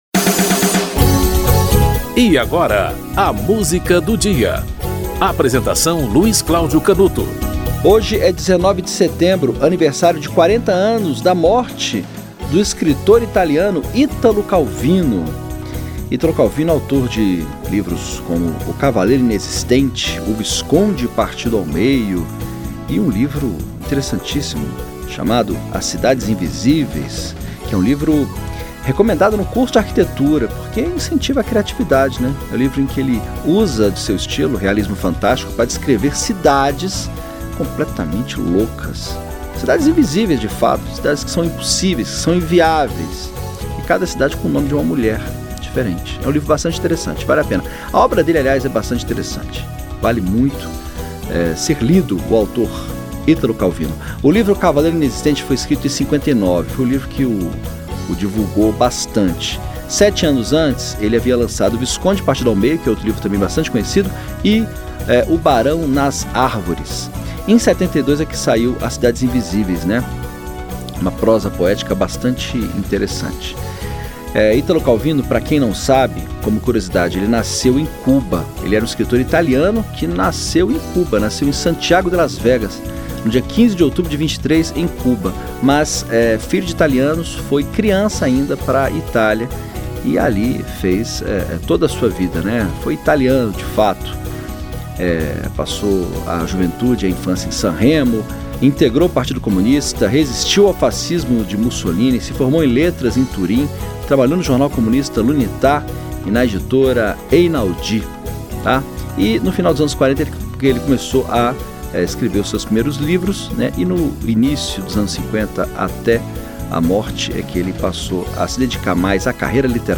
Dalva de Oliveira - Sábias Palavras (Marino Pinto, Mario Rossi)
O programa apresenta, diariamente, uma música para "ilustrar" um fato histórico ou curioso que ocorreu naquele dia ao longo da História.